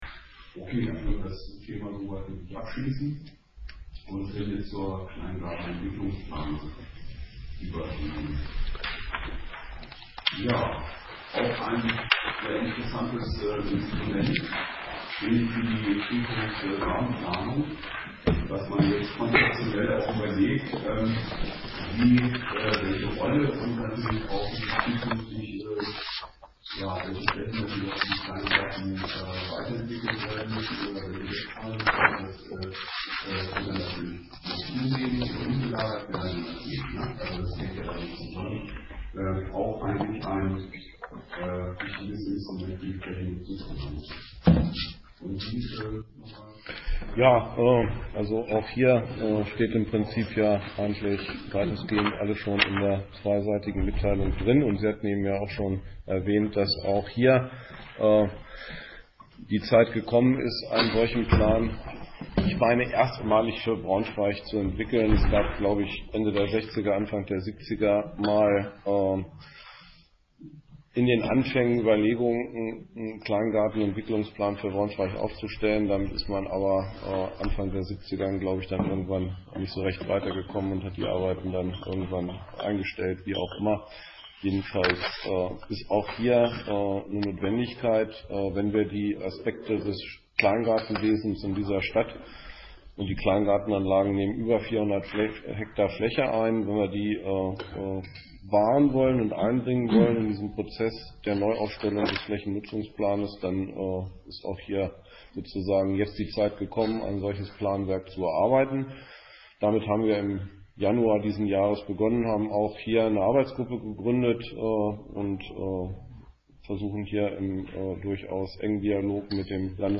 Audiomittschnitt zum Thema, aus dem Grünflächenausschuss 8.3.2017